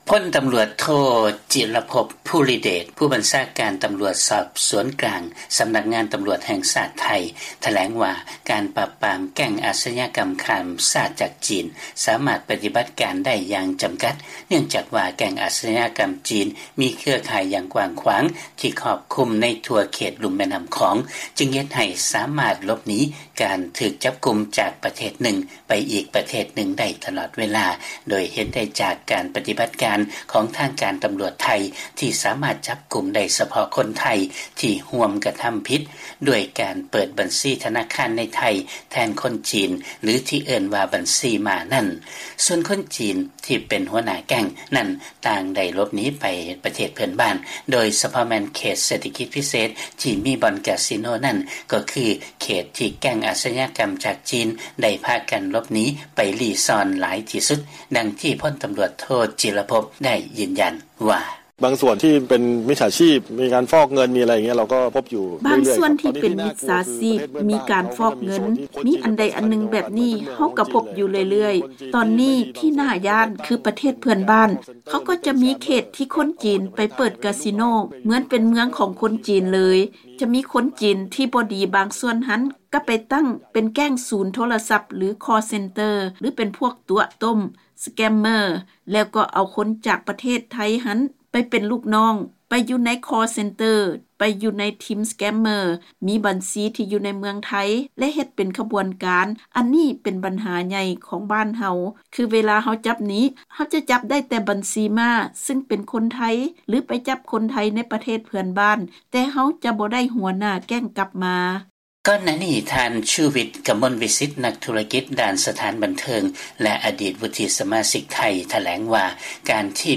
ເຊີນຟັງລາຍງານກ່ຽວກັບນັກລົງທຶນຈີນໃນເຂດເສດຖະກິດພິເສດກ່ຽວຂ້ອງກັບແກັ່ງອາດຊະຍາກຳຂ້າມຊາດ